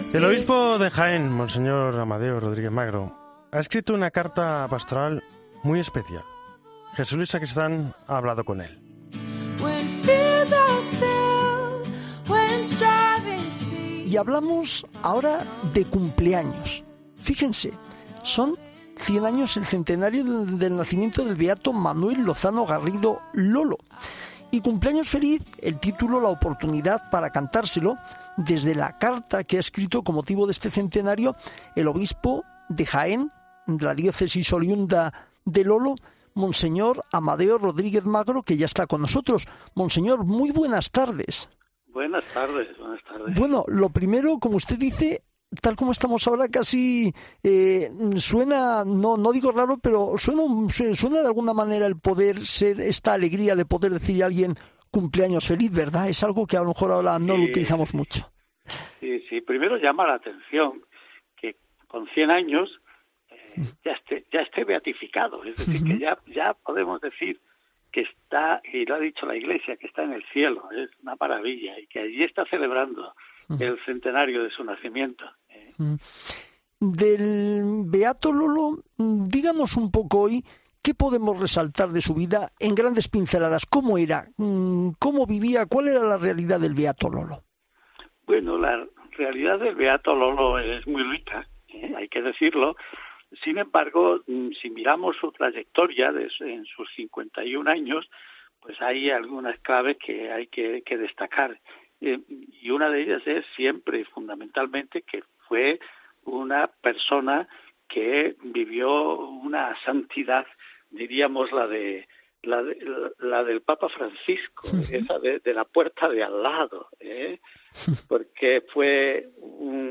Monseñor Amadeo Rodríguez ha recordado la vida del beato Manuel González "Lolo" en los micrófonos de 'El Espejo'